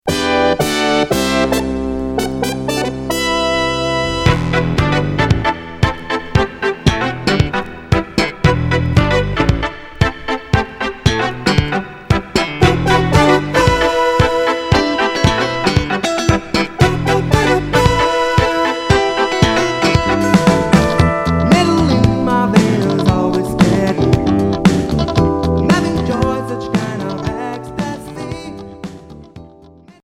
Pop funk Unique 45t retour à l'accueil